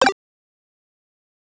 Chiptune Sample Pack
8bit_FX_C_02_03.wav